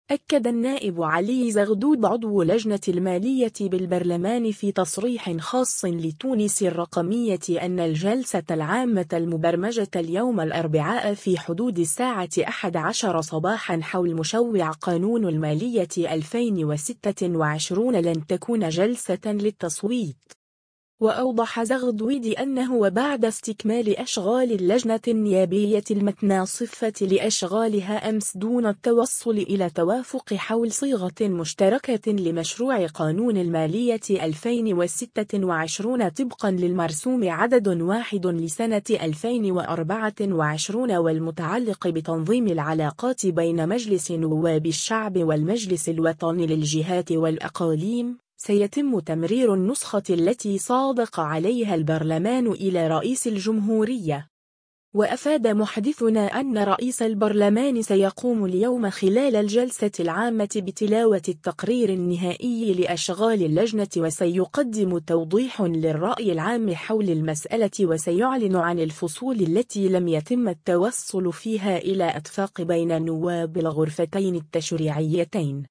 أكد النائب علي زغدود عضو لجنة المالية بالبرلمان في تصريح خاص لـ”تونس الرقمية” أن الجلسة العامة المبرمجة اليوم الأربعاء في حدود الساعة 11 صباحا حول مشوع قانون المالية 2026 لن تكون جلسة للتصويت.